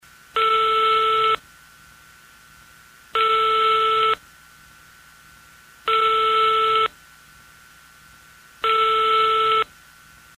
Звуки гудков телефона
Набор номера и звук телефонных гудков